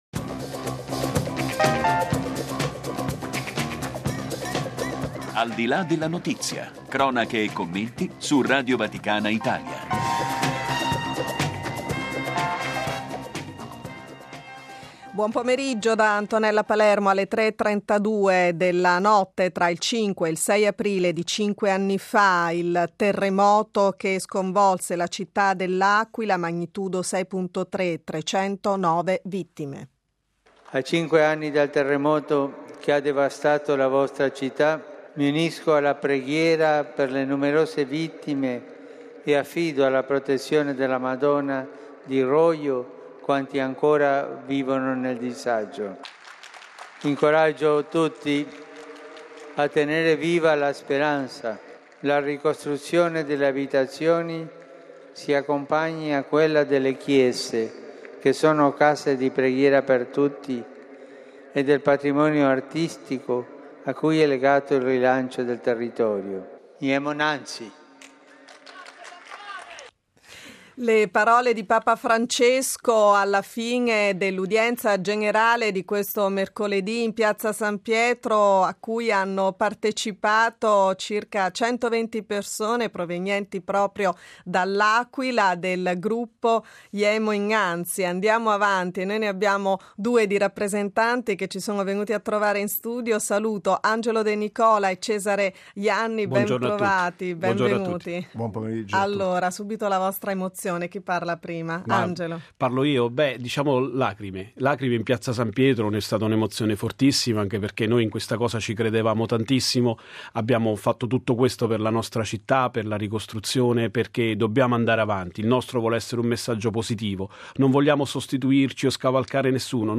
Ai nostri microfoni la testimonianza